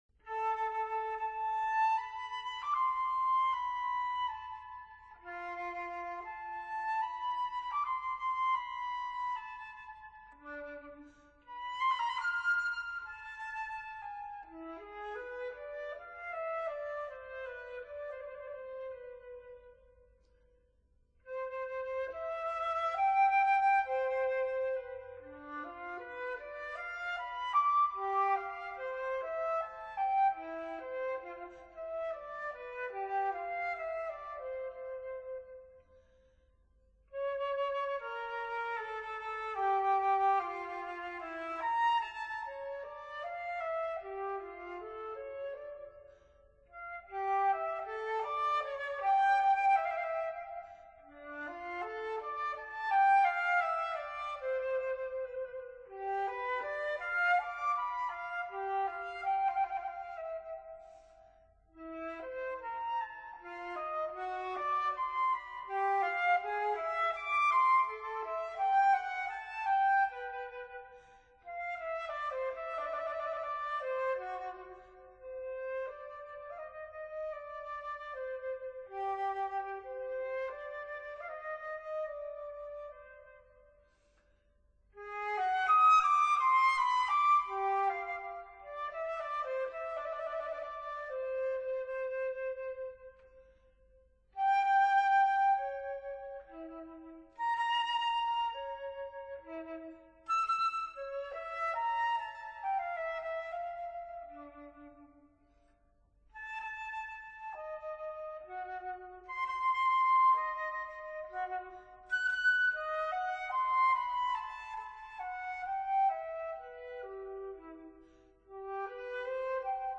Unaccompanied